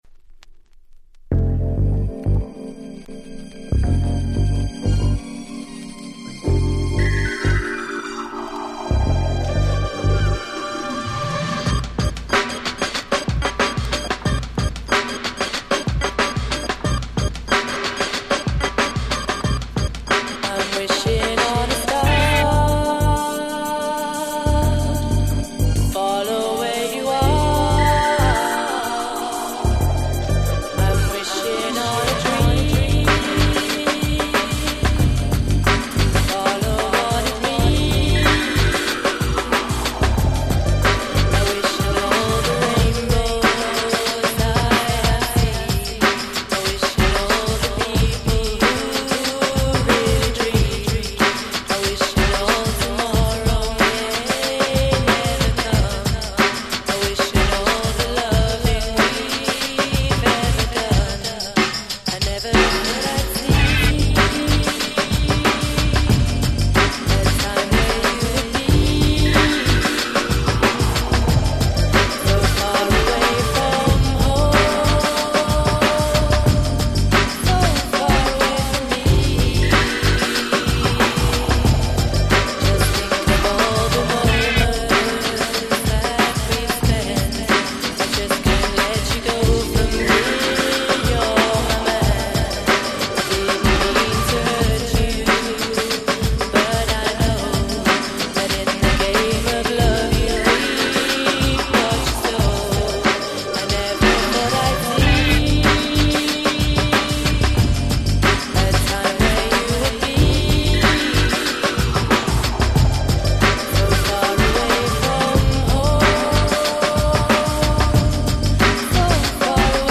89' Nice Cover R&B !!
Ground Beat Classics !!